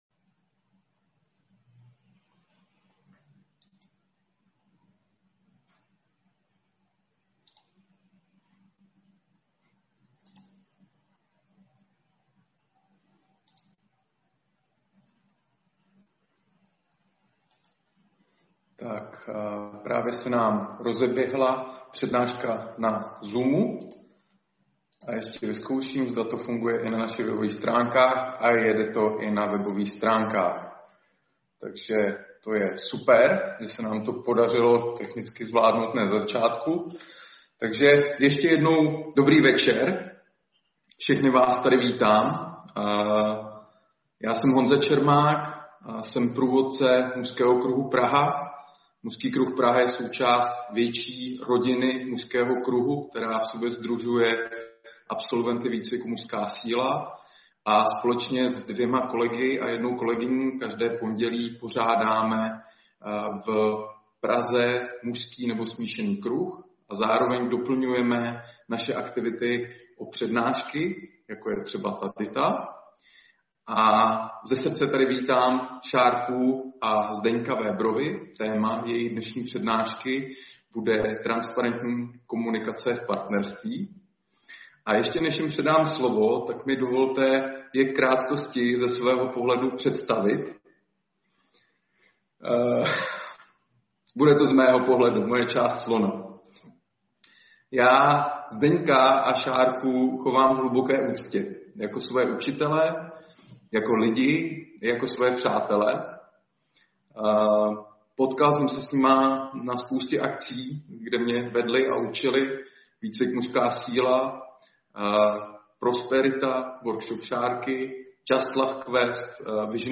Audio záznam přednášky Transparentní komunikace v partnerství ke stažení